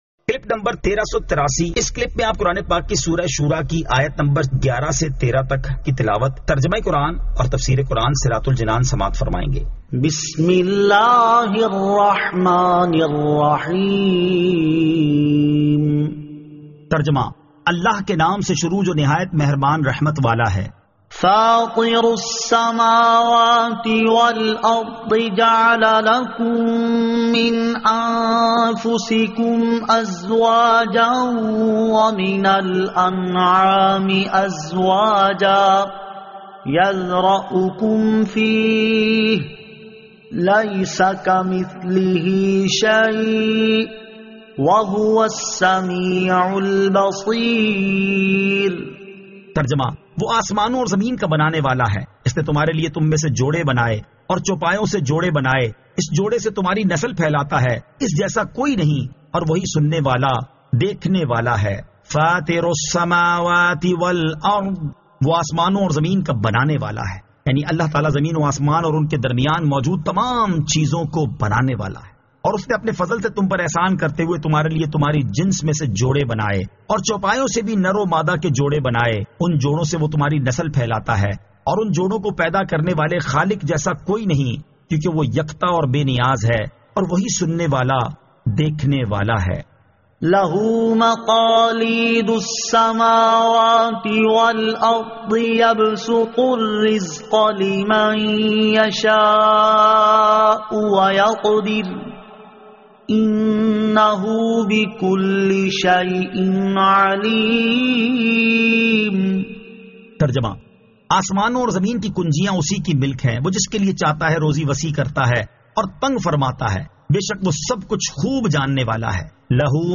Surah Ash-Shuraa 11 To 13 Tilawat , Tarjama , Tafseer